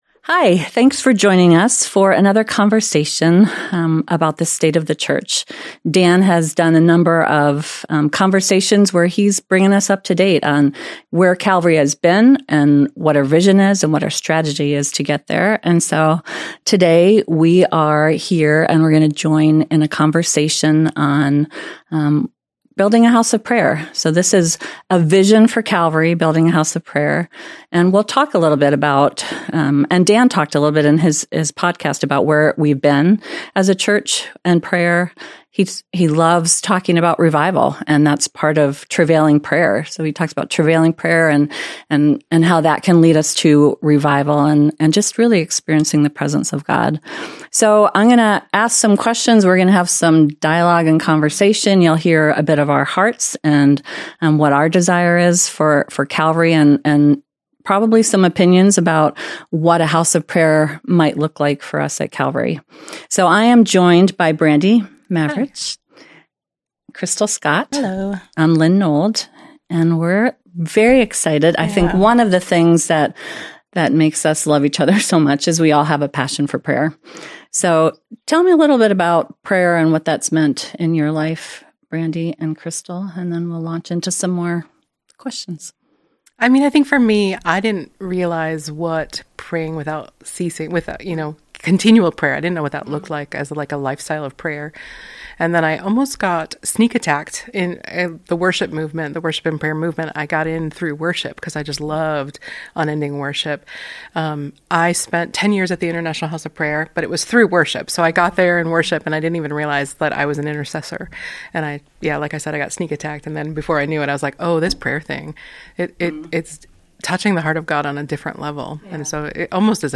Ep 12. State of the Church | A Conversation about Building a house of prayer | Calvary Portal | Calvary Portal